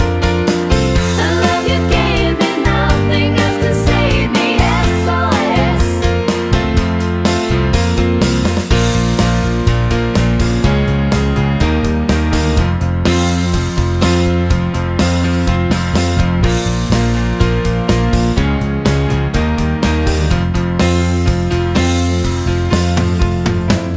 No Guitars Pop (1970s) 3:24 Buy £1.50